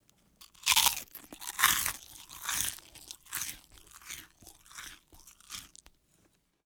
chew1.wav